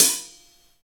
27 HAT.wav